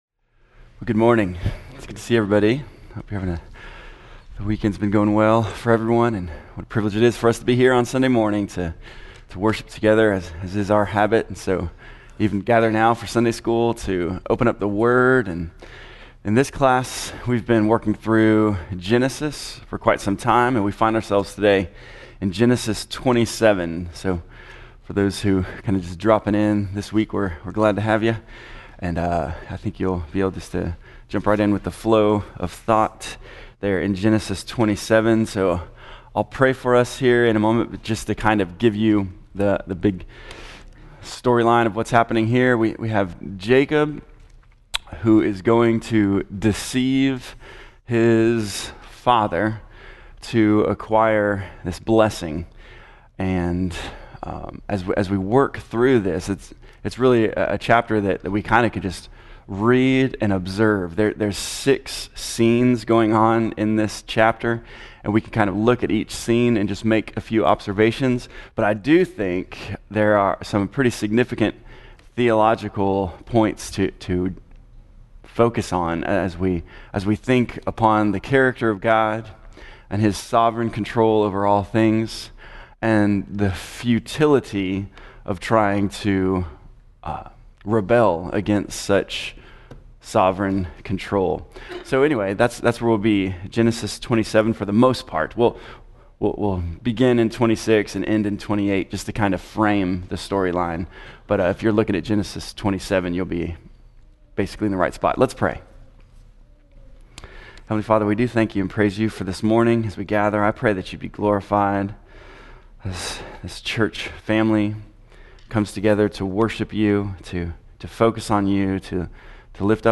Lesson 37 in the Genesis: Foundations Sunday School class.